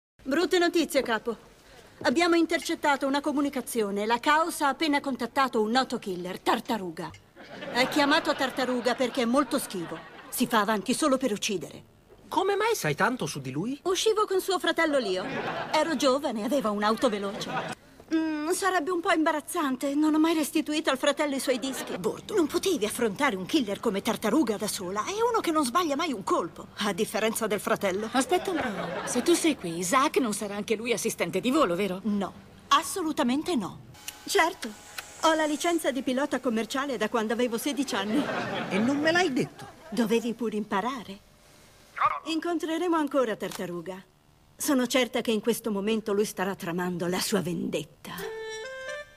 nel telefilm "Get Smart - Un detective tutto da ridere", in cui doppia Elaine Hendrix.